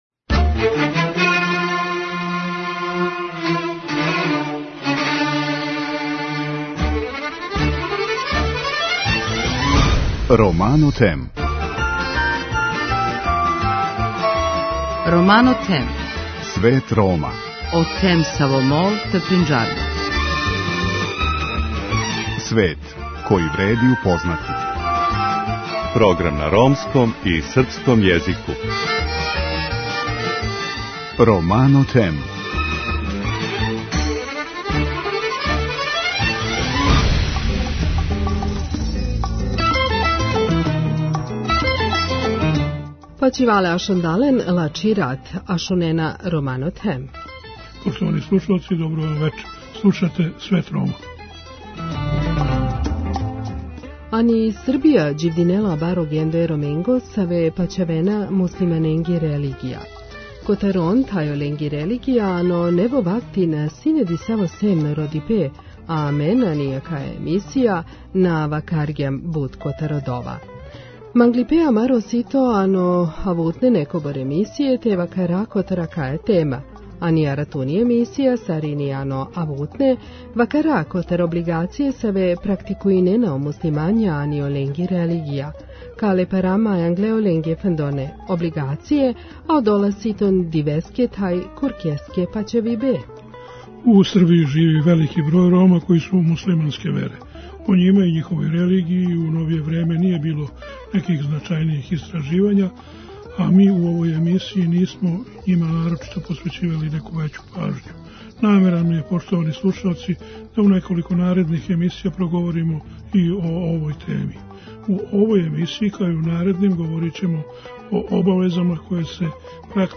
У вечерашњој емисији настављамо разговор